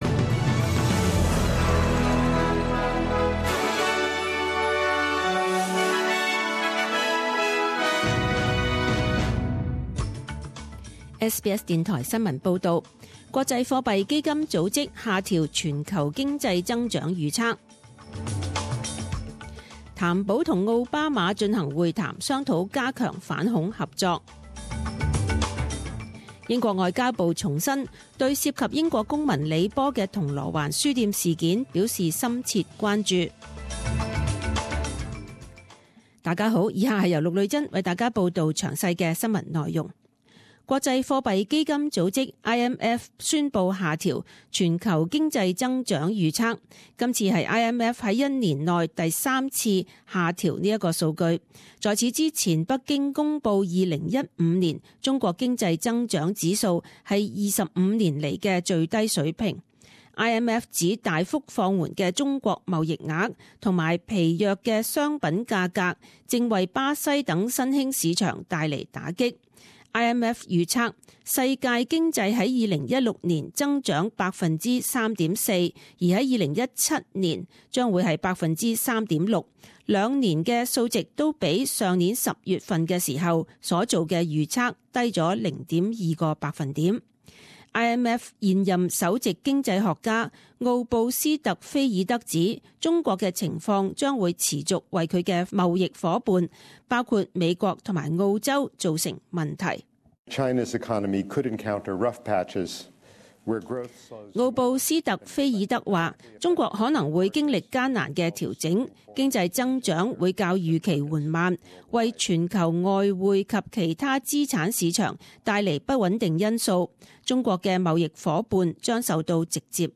十点钟新闻报导 （一月二十日）